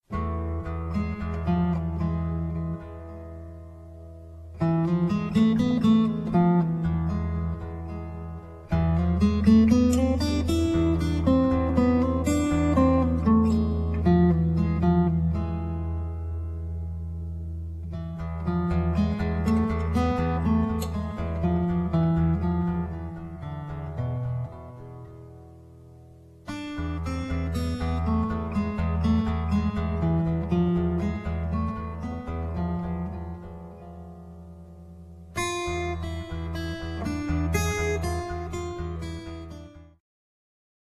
Etno, jazz, rock i coś jeszcze.